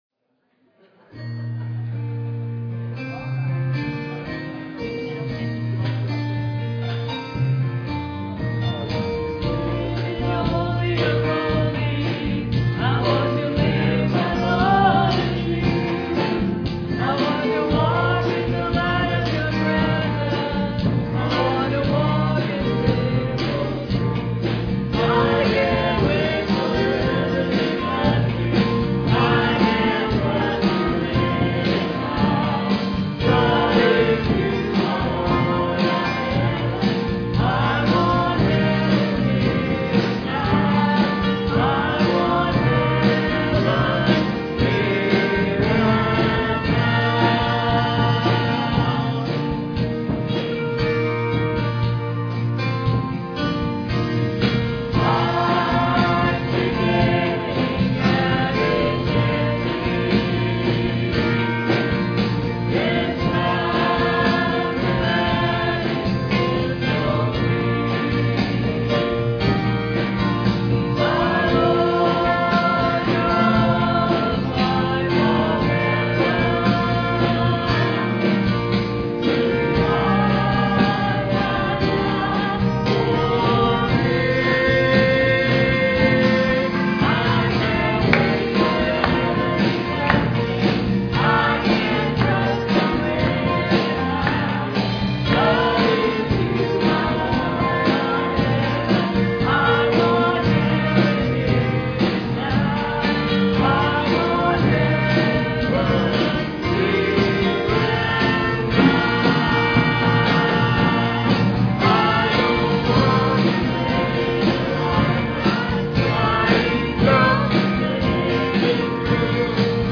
Piano and organ duet